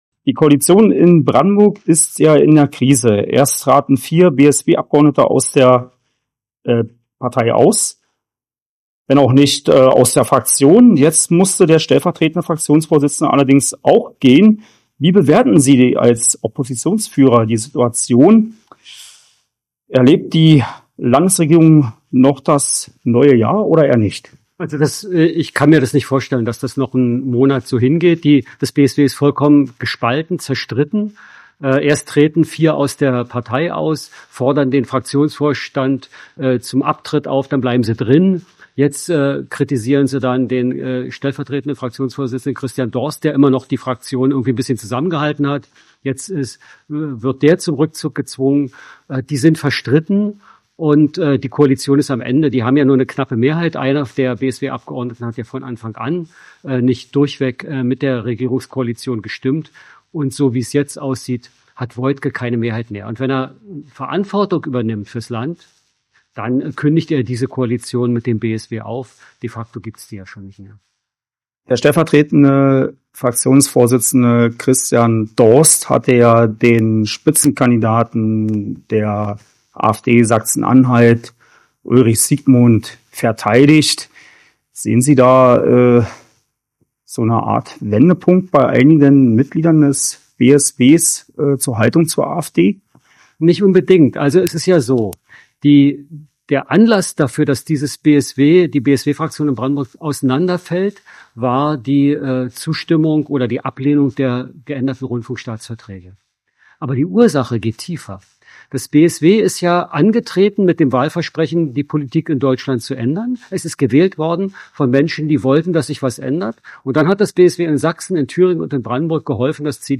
mit dem Oppositionsführer Christoph Berndt.